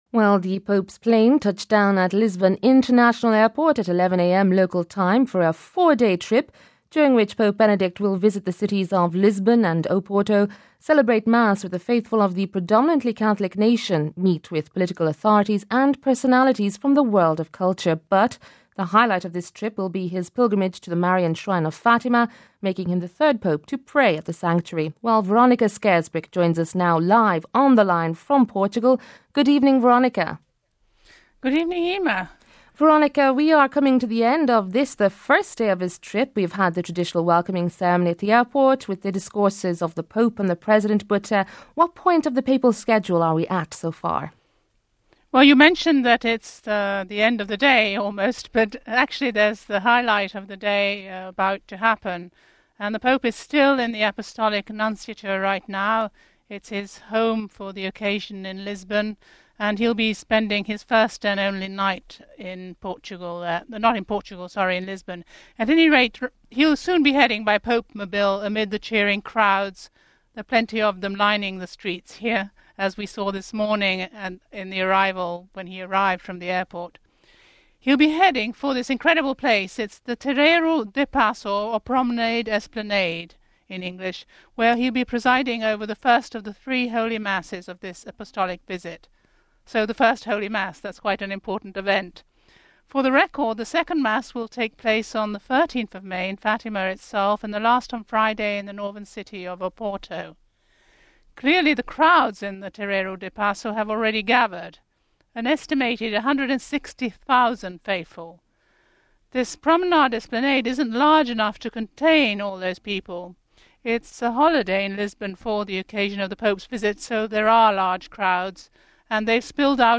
(11 May 10 – RV) Atlantic skies and the sun shining as a 32 strong children’s choir from the prestigious Lisbon Academy of Saint Cecilia sang the hallmark hymn marking this journey to welcome Benedict XVI on Portuguese soil for the first time , as Roman Pontiff .
And there were speeches , beginning with the welcoming party , the President who welcomed the pope at the start of this journey rife with meaning for Portugal describing him as a “messenger of hope at a time of uncertainty” ..